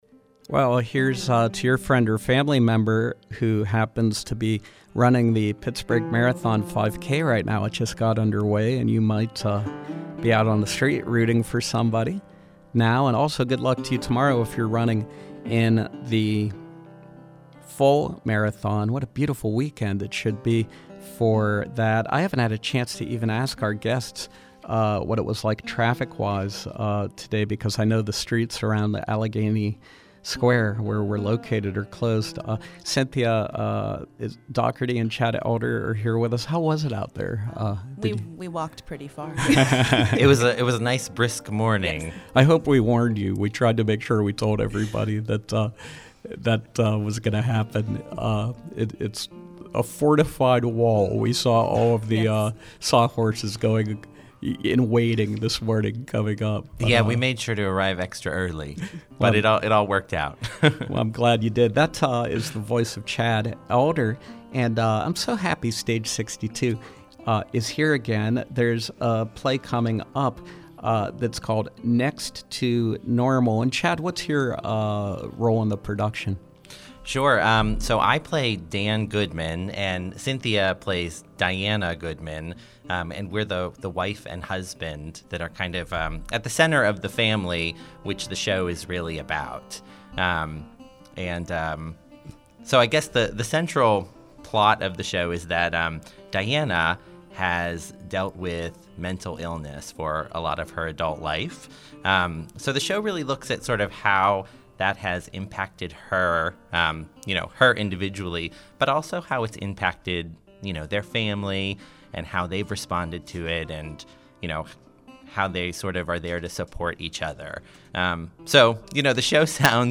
perform a selection from Stage 62‘s presentation of Next to Normal